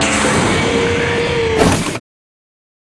Doors